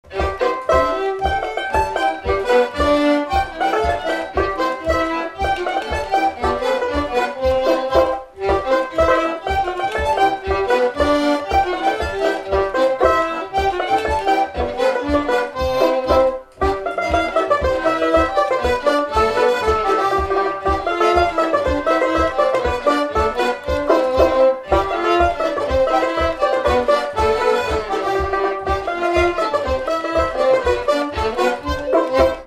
Instrumental
danse : séga
Pièce musicale inédite